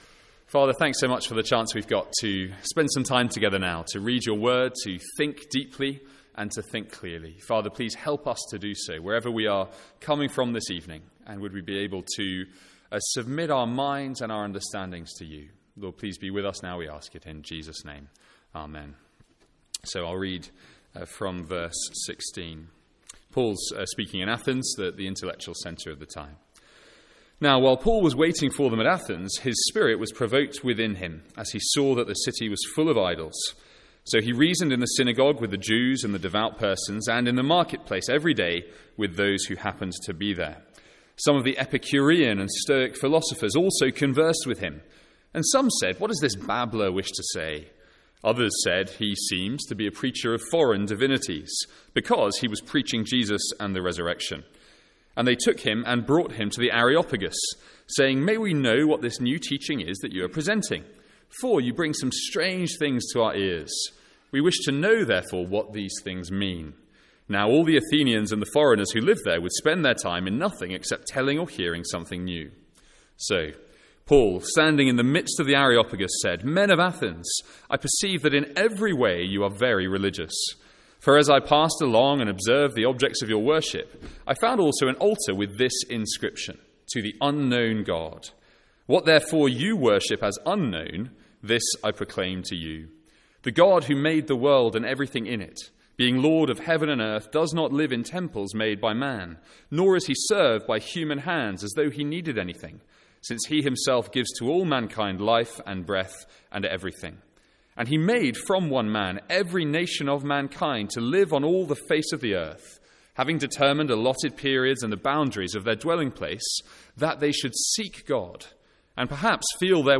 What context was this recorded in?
From our evening series: If you could as God one question...